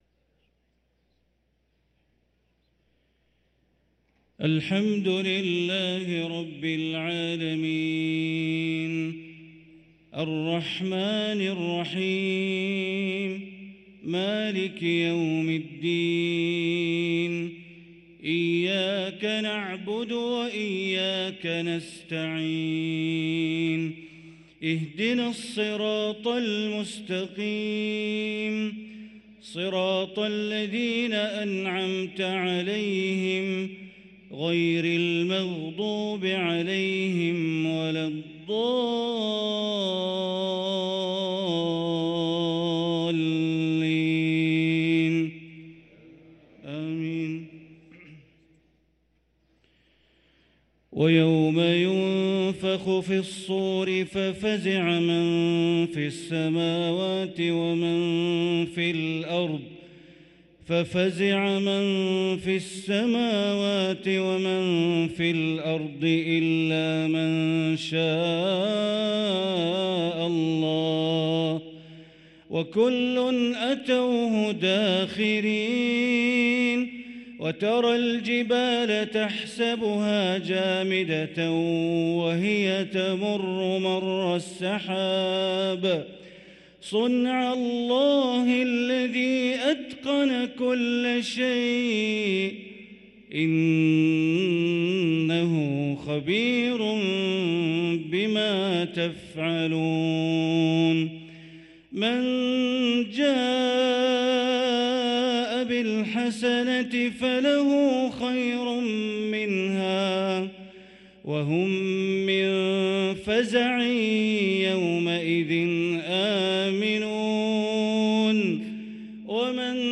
صلاة العشاء للقارئ بندر بليلة 16 شعبان 1444 هـ
تِلَاوَات الْحَرَمَيْن .